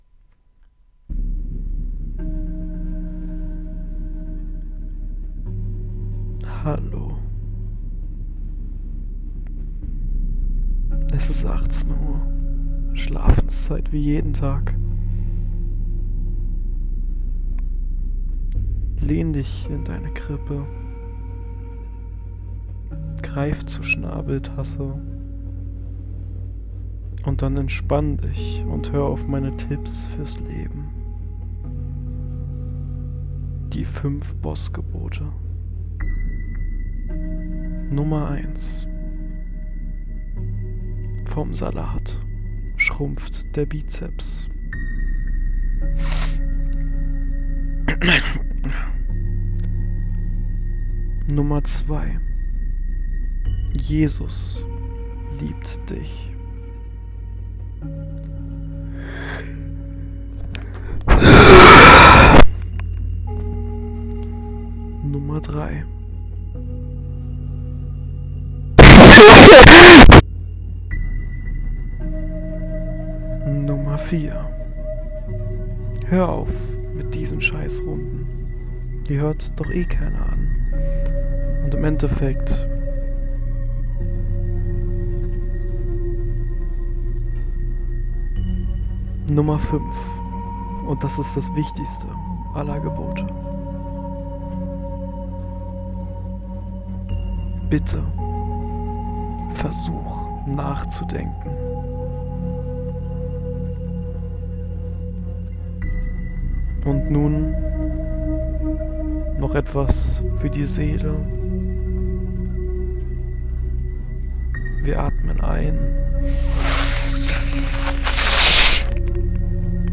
Tipp: Lass die übersteuerten Sound das kommt einfach nicht gut an